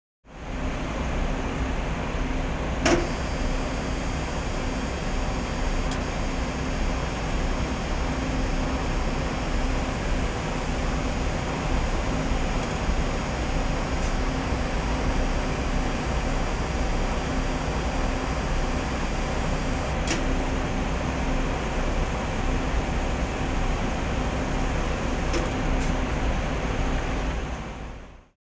В этой подборке посвященной звукам приборов, которые можно бесплатно скачать и слушать онлайн, вы найдете самые разные примеры — медицинских, лабораторных, космических и других шумов приборов.
Звуки приборов в лаборатории:
zvuki-priborov-v-laboratorii.mp3